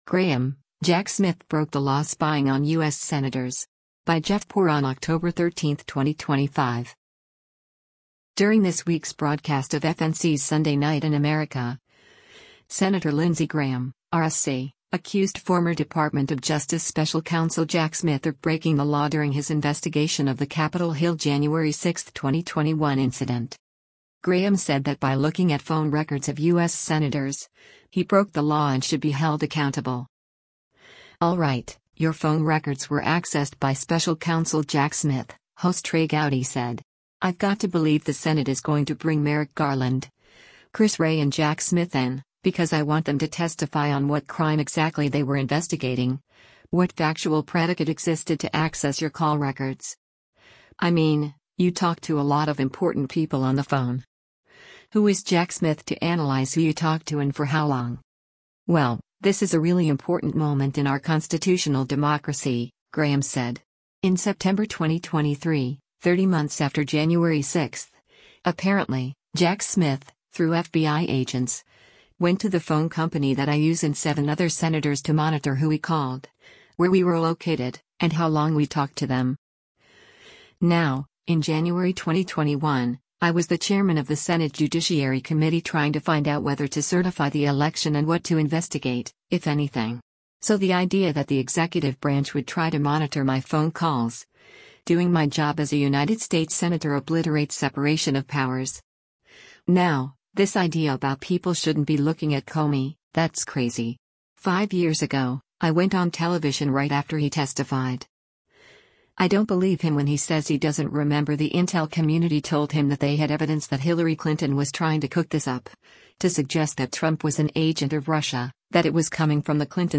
During this week’s broadcast of FNC’s “Sunday Night in America,” Sen. Lindsey Graham (R-SC) accused former Department of Justice special counsel Jack Smith of breaking the law during his investigation of the Capitol Hill January 6, 2021 incident.